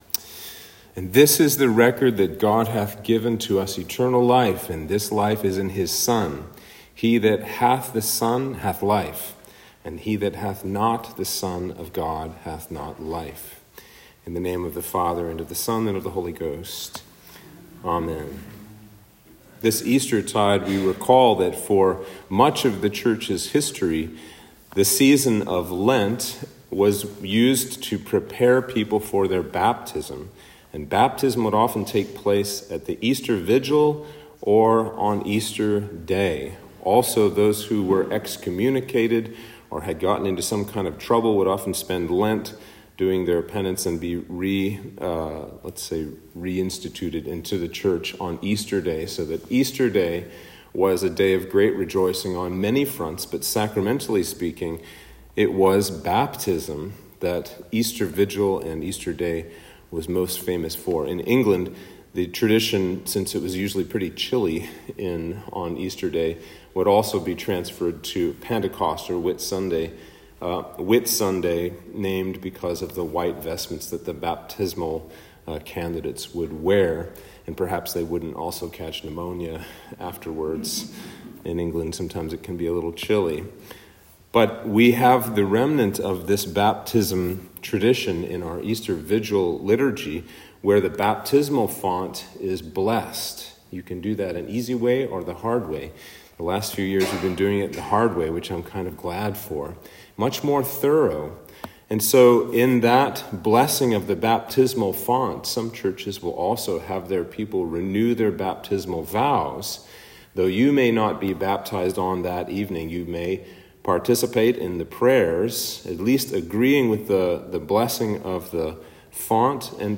Sermon for Easter 1